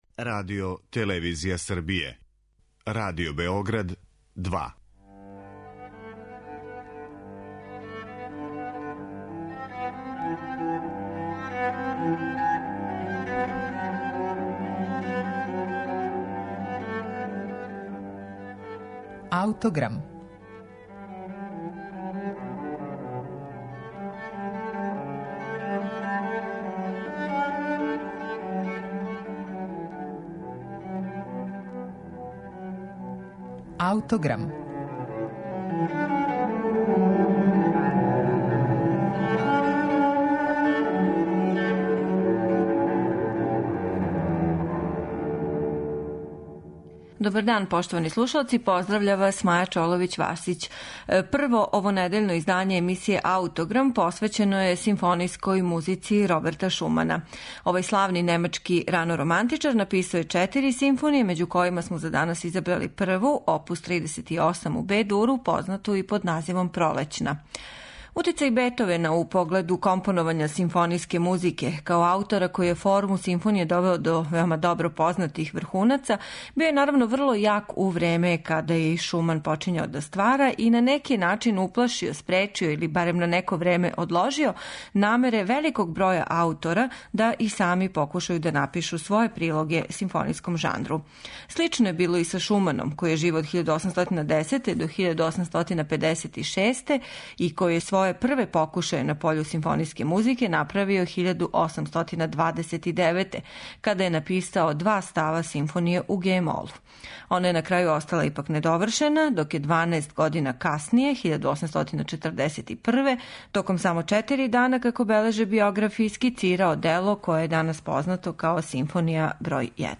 Симфонију бр. 1 Роберта Шумана ћете слушати у интерпетацији Симфонијског оркестра Југозападног радија, којим диригује Марек Јановски.